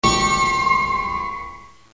Campane glide
Suono metallico campanoso tenebroso, con slittamento di frequenza. Effetto elettronico.